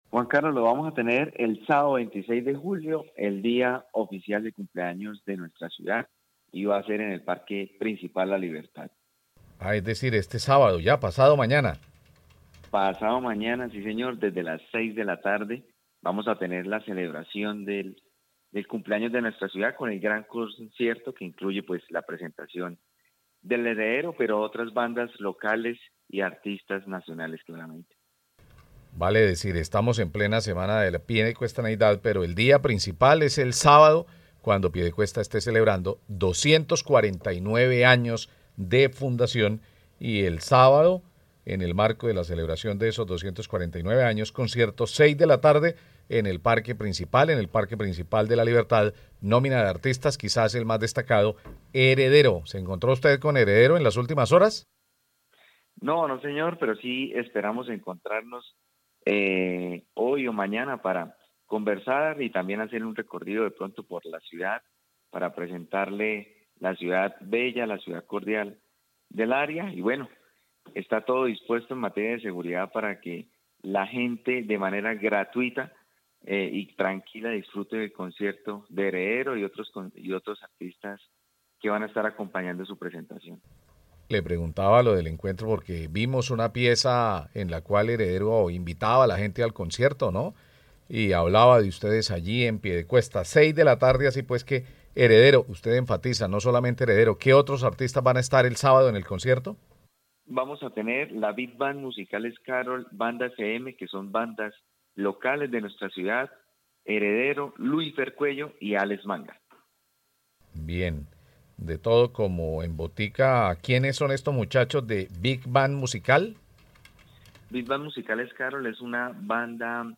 Jeferson Osorio, secretario de Cultura de Piedecuesta